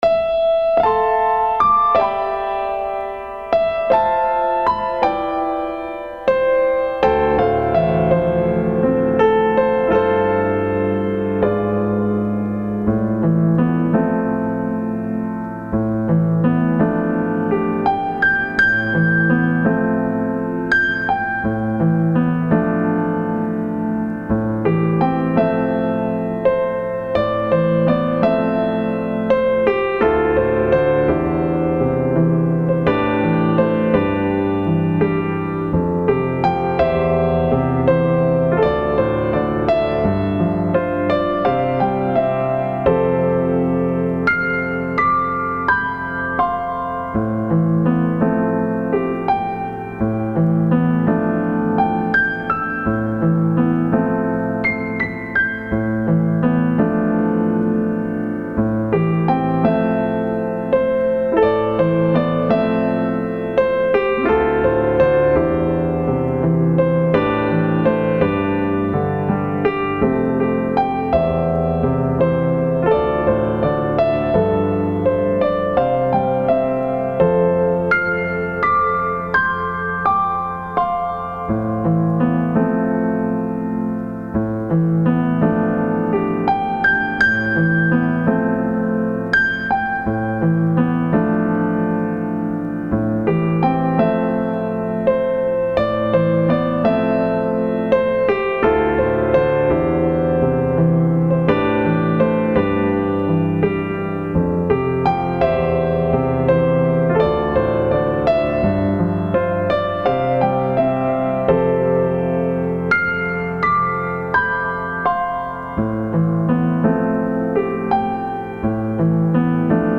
Habe hier mal ein Piano Stück hochgeladen, eine langsame Ballade.
Ich kann nur sagen, die Aufnahme entstand hier in Schweiz und ich war dabei.